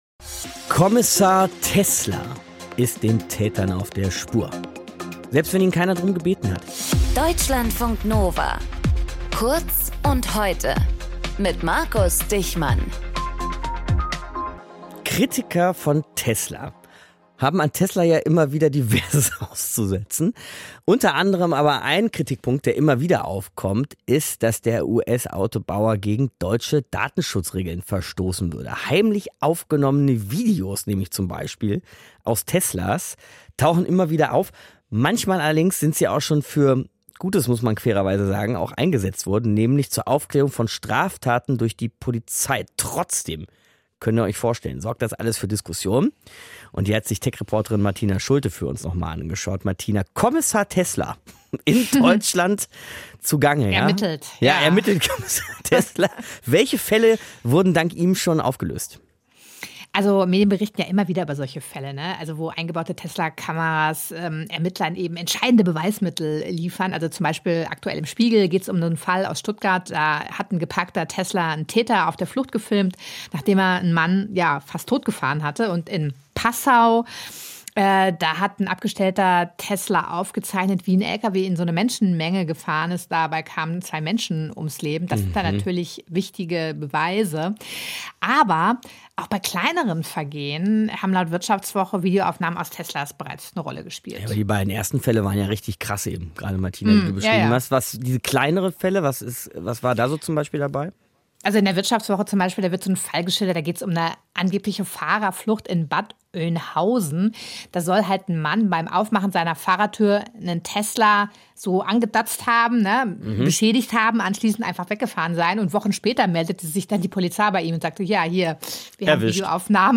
Moderator
Gesprächspartnerin